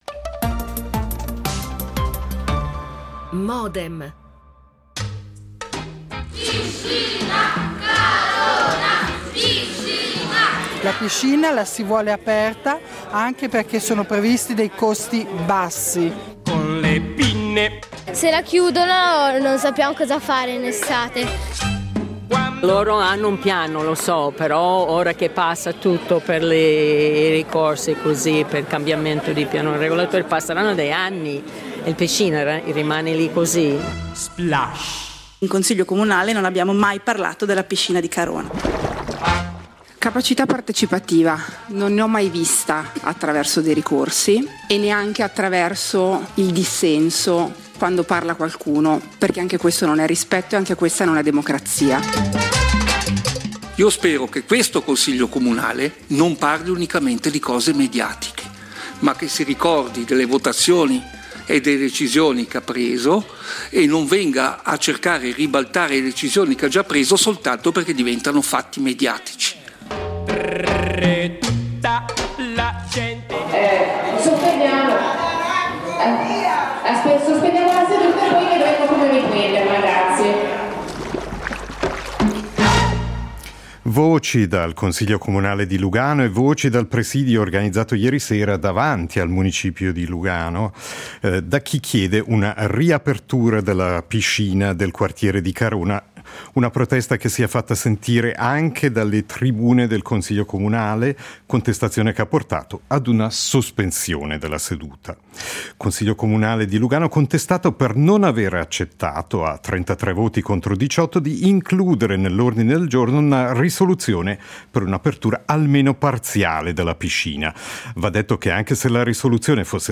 Fra fischi e applausi il Consiglio comunale di Lugano non fa marcia indietro